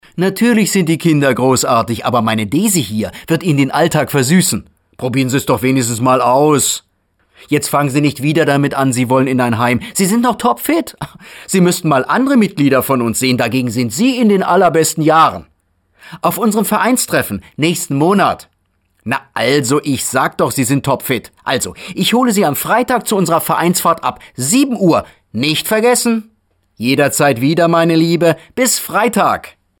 deutscher Profi-Sprecher. Breites Spektrum von sachlich bis ausgeflippt (Trickstimme).
Sprechprobe: eLearning (Muttersprache):